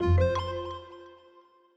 Longhorn 8 - Notify System Generic.wav